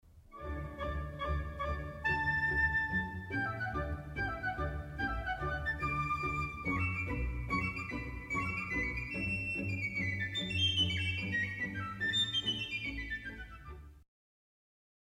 Può essere di metallo o legno e il suo suono è particolarmente brillante e penetrante.
suono dell'ottavino
ottavino.mp3